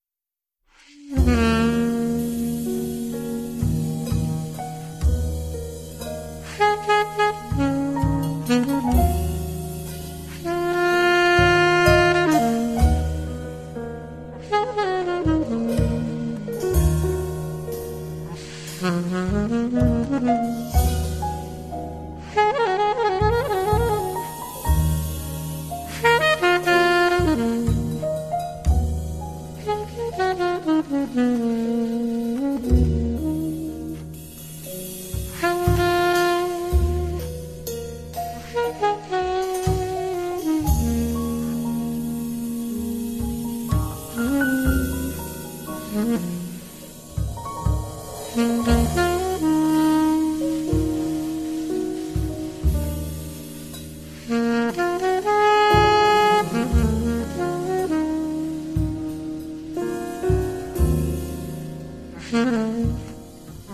Drums Recorded at Volta Studio, Tokyo
Alto Sax
Piano
Bass
Drums